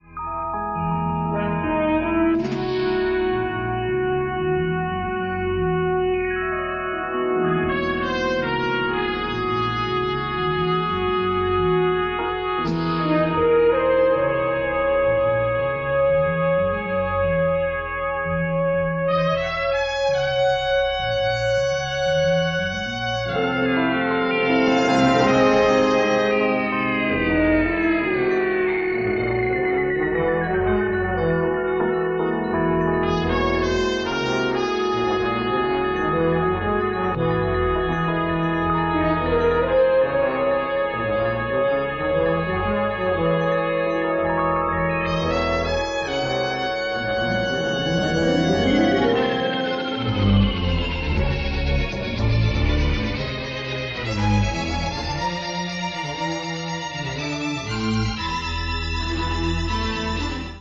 innovative use of synthesizers combined with jazz elements